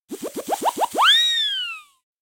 cartoon_whistle